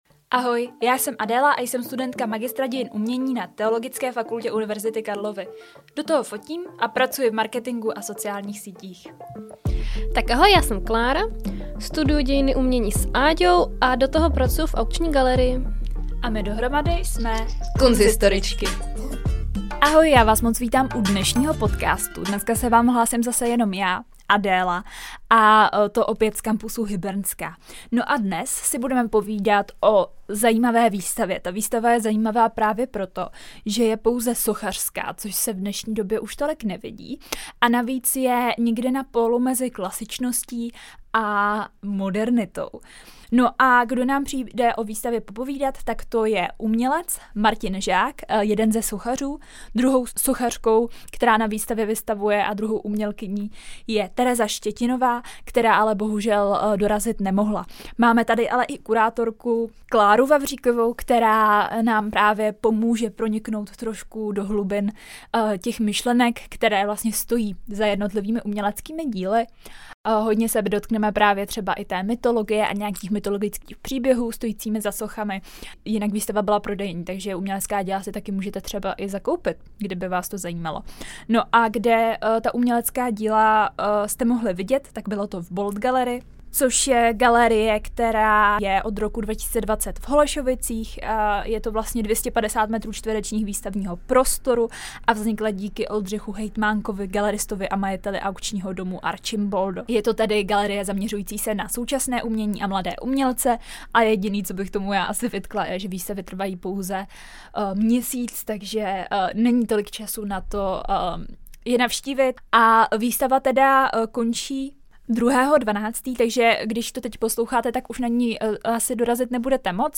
Čistě sochařská výstava není již tak běžně k vidění - možná právě kvůli horšímu prodeji sochařských děl, náročné manipulaci s těžkým kamenem a nebo socha prostě vychází z módy? Téma zpracování umění do sochy řešíme v rozhovoru